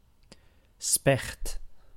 Ääntäminen
IPA: /pik/